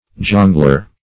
Jongleur \Jon"gleur\, Jongler \Jon"gler\, n. [F. jongleur. See